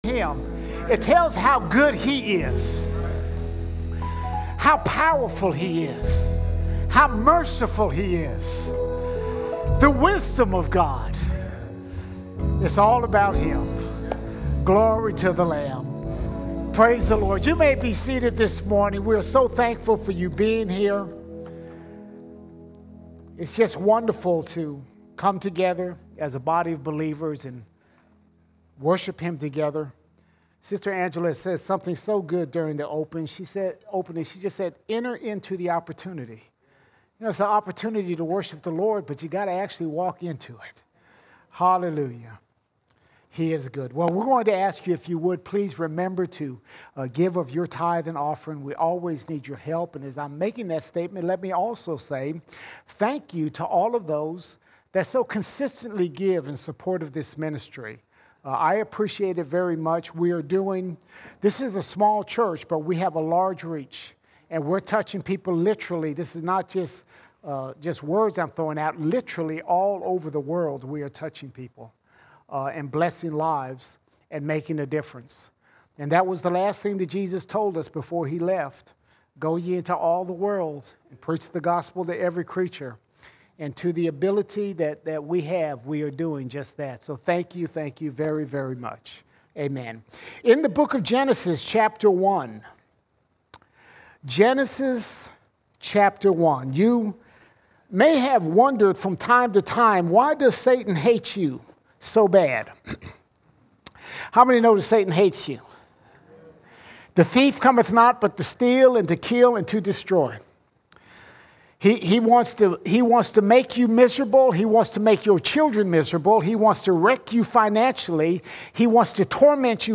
2 October 2023 Series: Sunday Sermons Topic: the world All Sermons Image Bearers Image Bearers Man is made in the image of God.